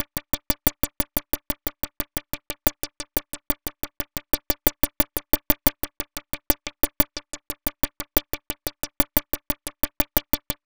Synth 43.wav